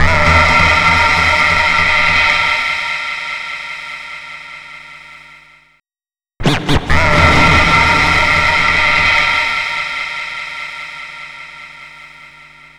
09-Scratched up Vox.wav